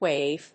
/wéɪv(米国英語), weɪv(英国英語)/